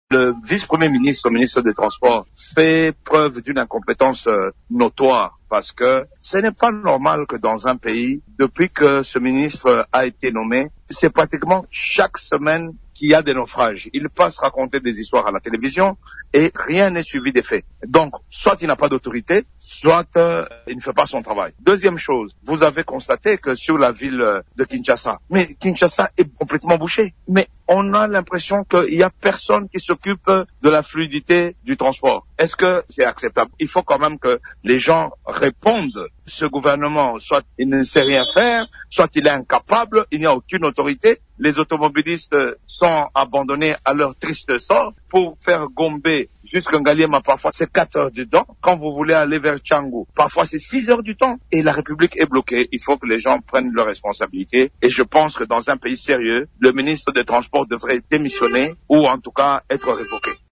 C'est ce qu'a déclaré le député national Christian Mwando Nsimba mercredi 24 décembre sur Radio Okapi.
Cet élu de Kabalo, province du Tanganyika et président du groupe parlementaire Ensemble à l’Assemblée nationale, pense que la persistance de ces phénomènes reflète l’incompétence du ministre du secteur des Transport en RDC: